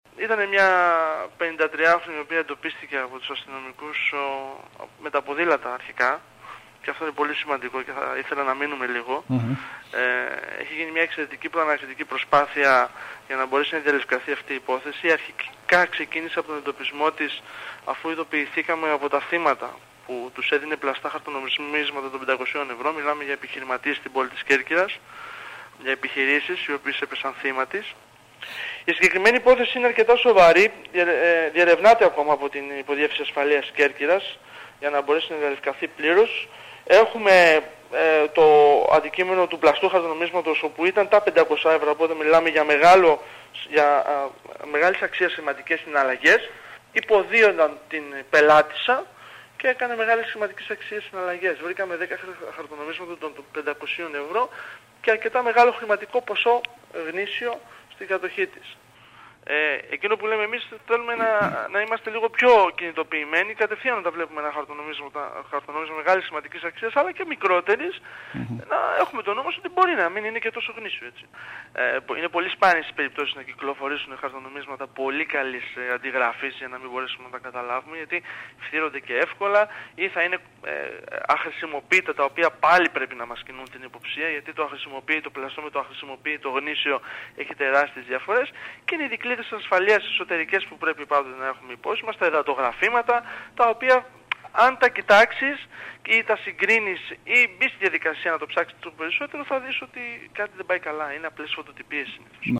Για το θέμα μίλησε στο σταθμό μας